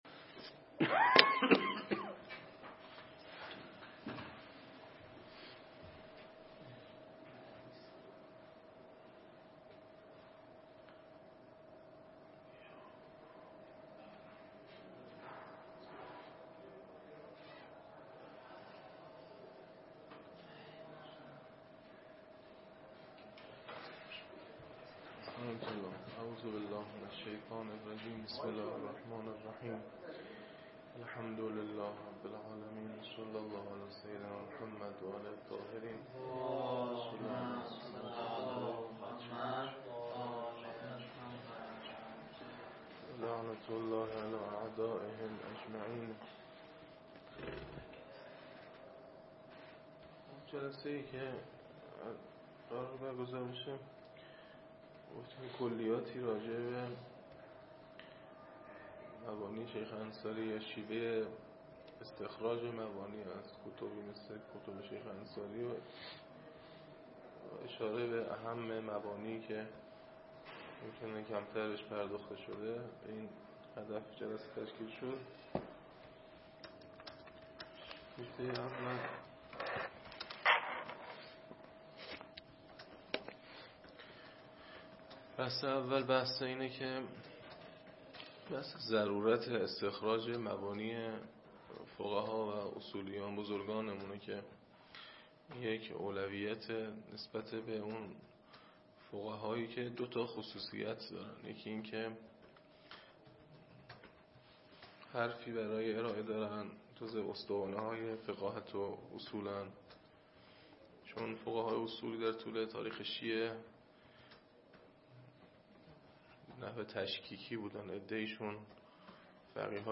نشست علمی